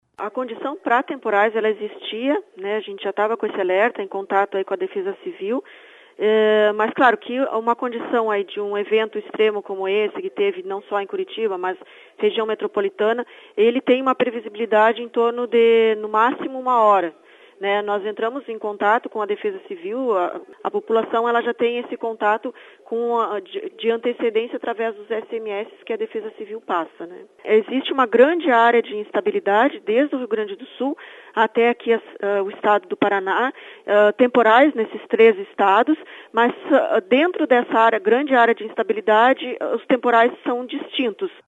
A meteorologista